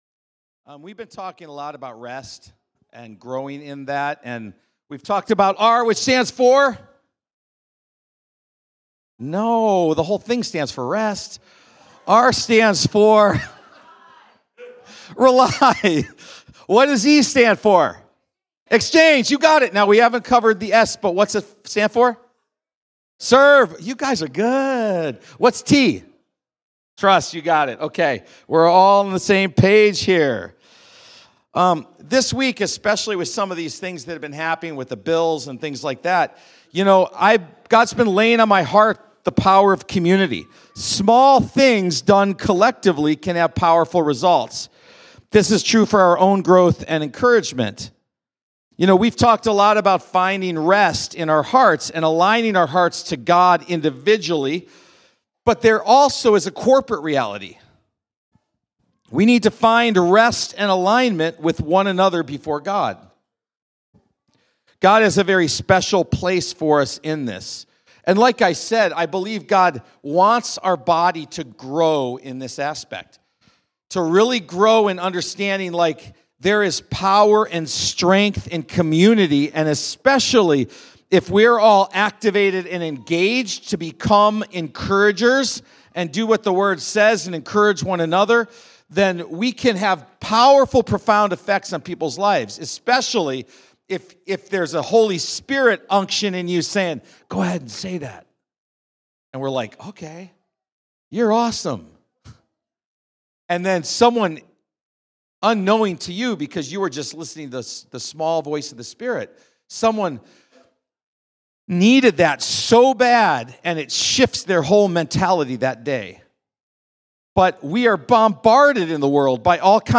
2.9.25-Sunday-Service.mp3